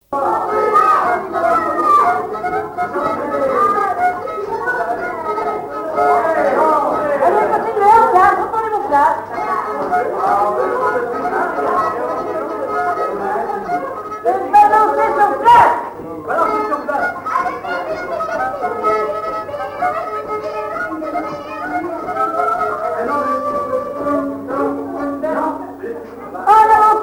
danse : quadrille
Musique, bal, émission de radio
Pièce musicale inédite